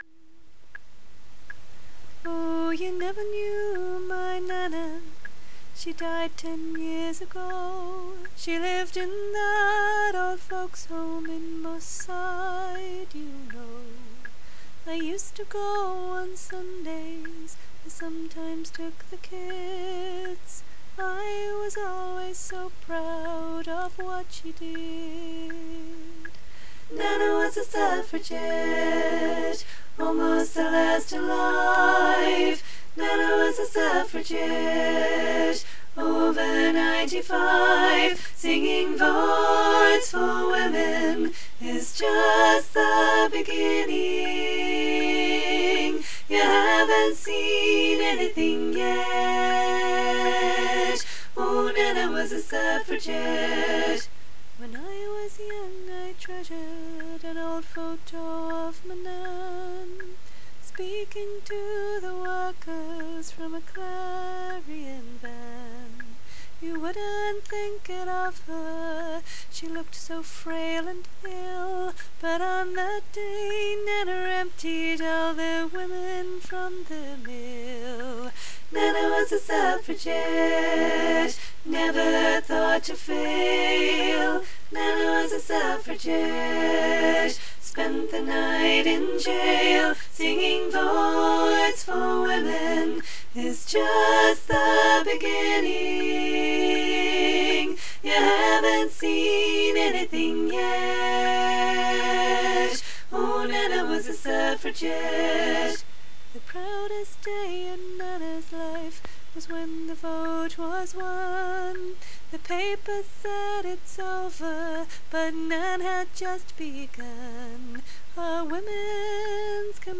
Nana Was a Suffragette for SCF19 SATB - Three Valleys Gospel Choir